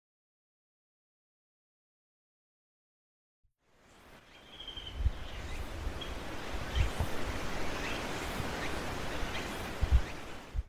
• ほか動物の声（屋久島にて収録）
アオゲラ